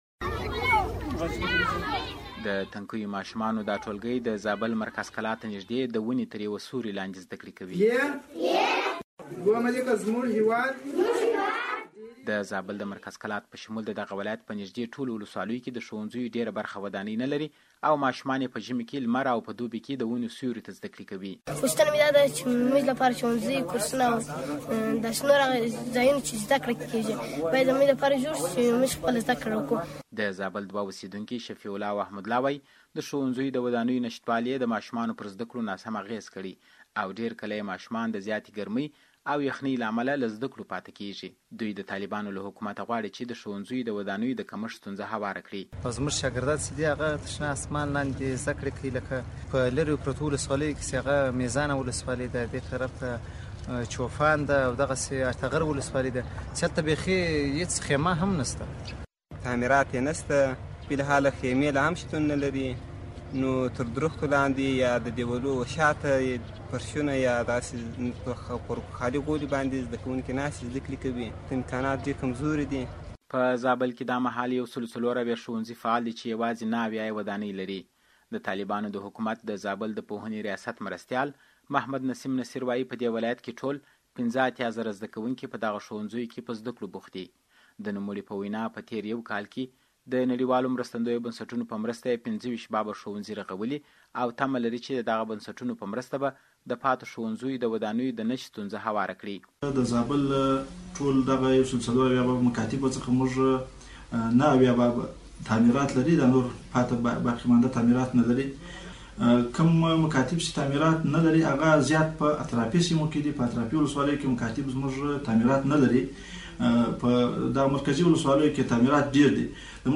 د زابل راپور